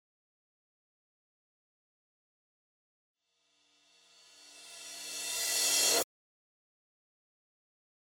このようにリバース（反転）しましたね！！！
シーーーーーーーーーーーーーーってなりましたね！！
こちらがリバースシンバルの作り方です。